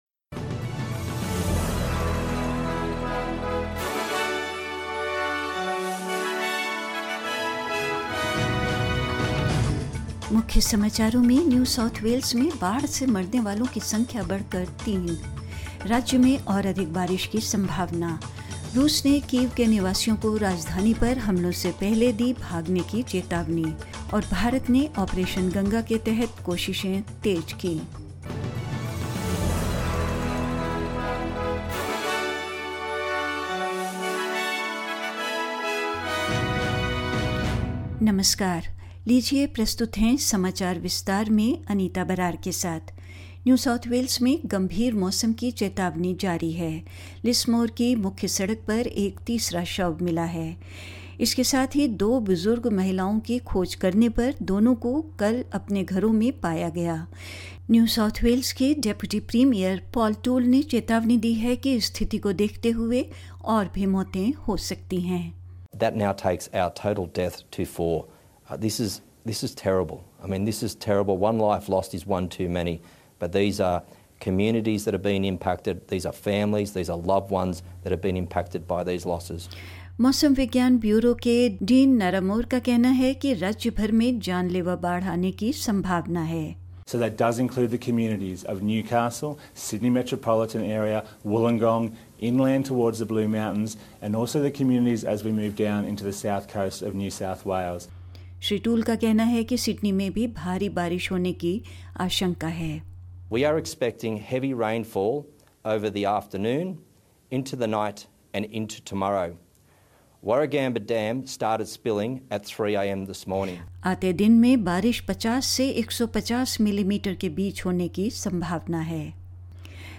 In this latest SBS Hindi bulletin: The death toll from New South Wales floods has risen to three, as the state braces for further rainfall; Russia warns residents of Kyiv to flee ahead of attacks on the capital; Russia and Belarus continue to face punishment across international sport and more news